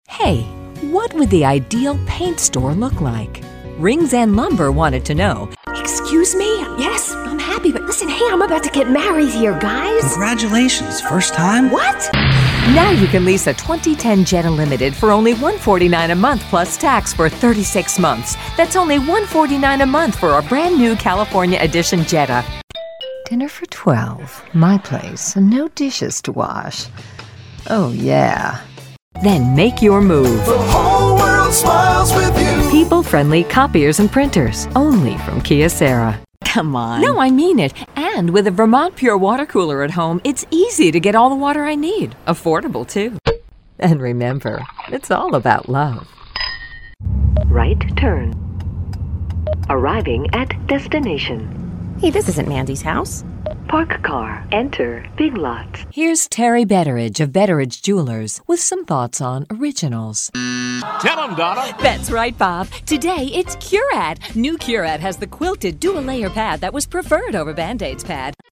American Female Voice Talent, Narrator, Voice Over commercials, telephone voice, video games, e-learning and medical narrations, characters too
englisch (us)
Sprechprobe: Werbung (Muttersprache):